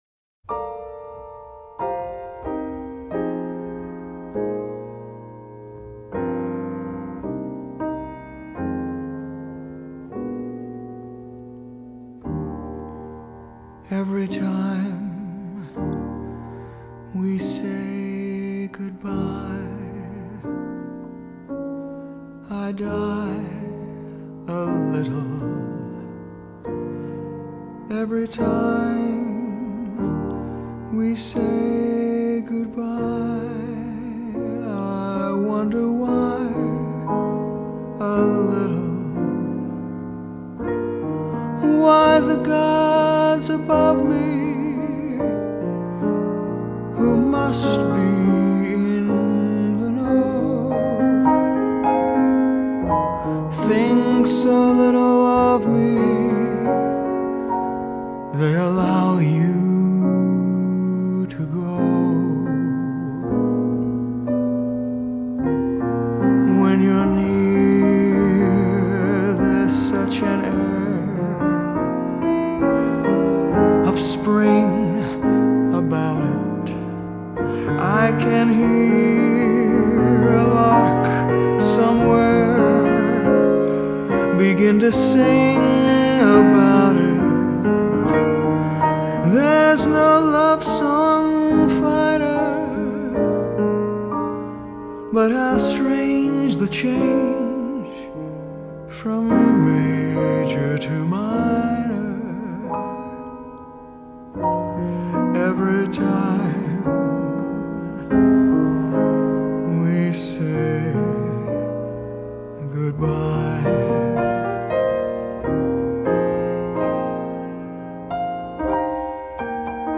1944   Genre: Musical   Artist